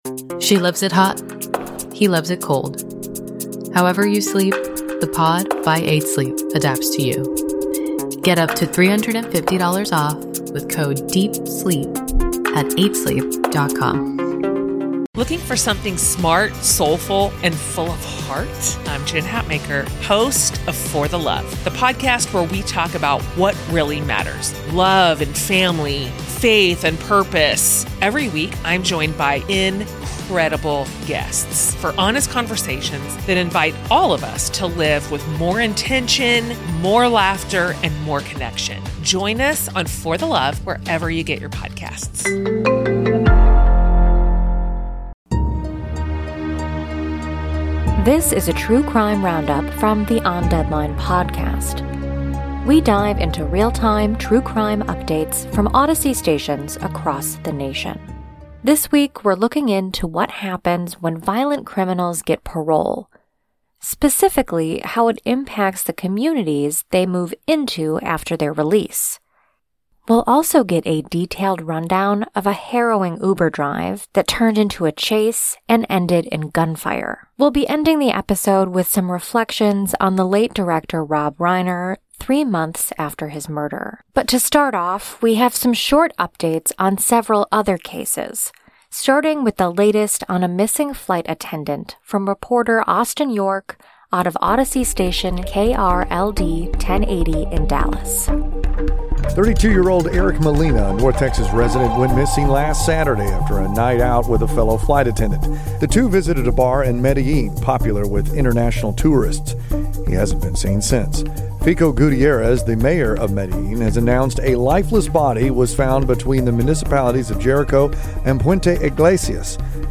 Featuring audio from KRLD 1080 in Dallas, WBBM Newsradio in Chicago, KMOX in St. Louis, WBEN News Talk in Buffalo, the Dana & Parks Show out of KMBZ in Kansas City and KNX News out of Los Angeles.